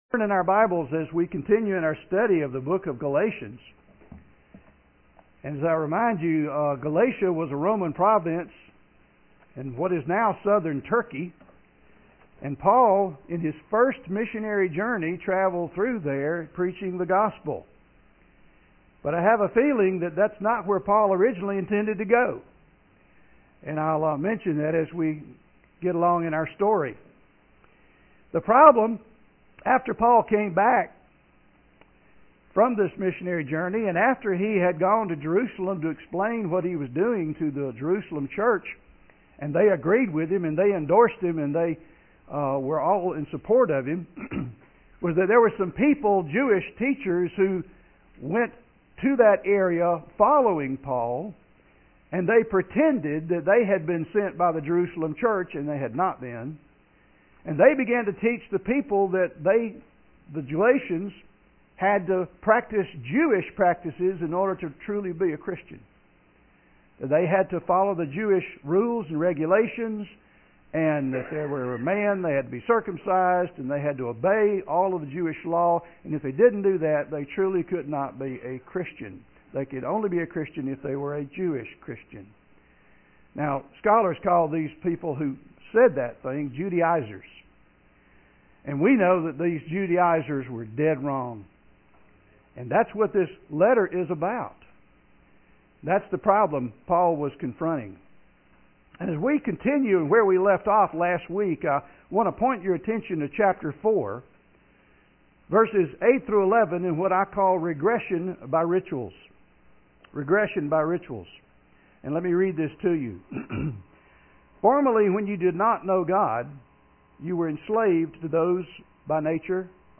Sermon Series